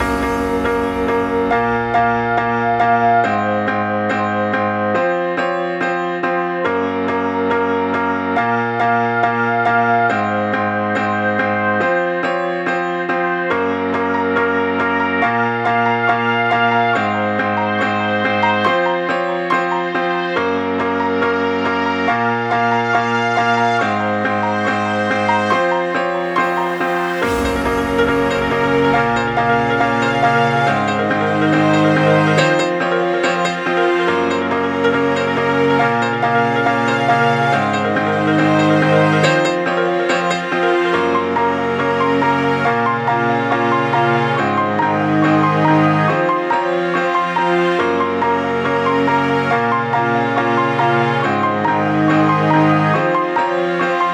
It seems to be a sort of melodic pop , dubstep song, with trance elements i could see it having potential on the radio and on YouTube, and i think a lot of people would like this, it also fit's in with my initial project plan, i could upload this to BBC Introducing and receive some radio plays. I think it fit's in with a wide variety of people, some people might be more focused on the lyrics , while others might completely dismiss the lyrics but focus on the more melodic chilled vibes of the song.